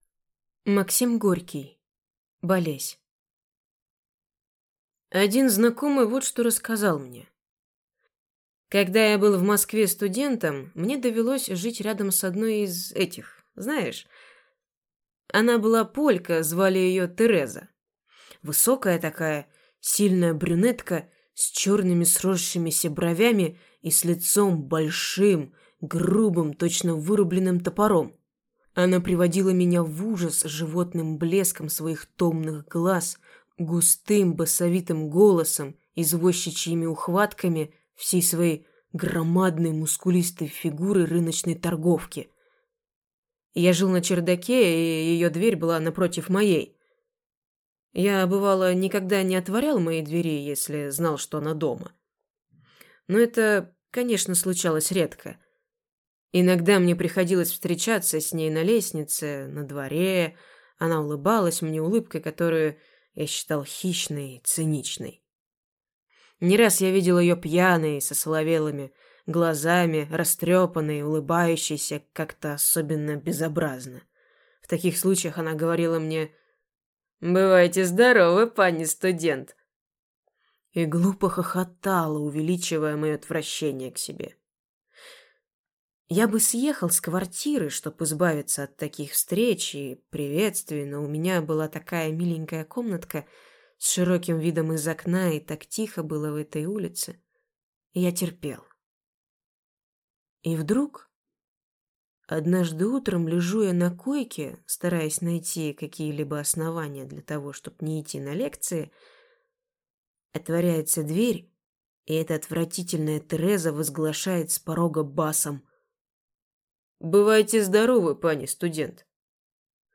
Аудиокнига Болесь | Библиотека аудиокниг